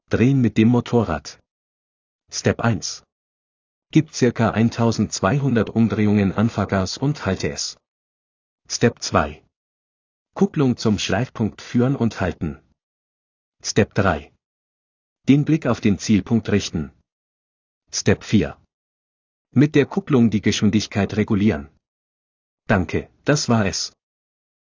Bike-Drehen.m4a